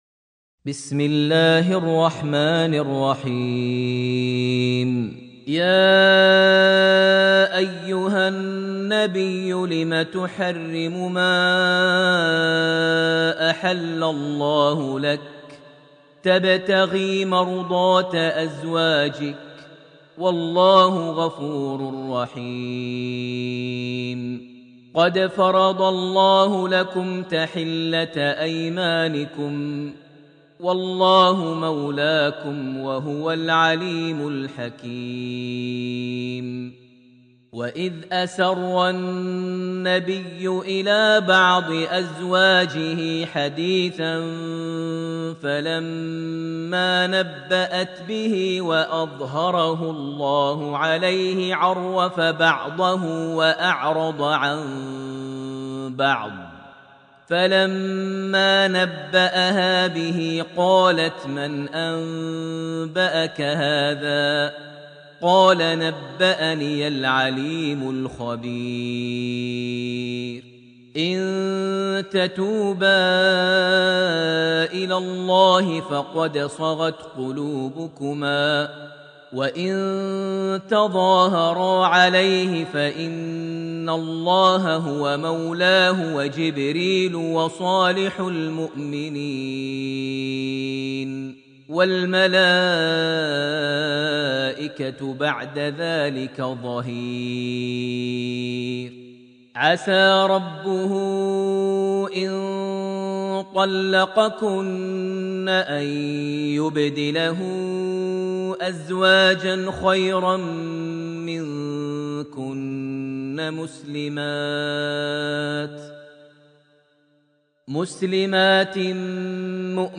surat Altahrim > Almushaf > Mushaf - Maher Almuaiqly Recitations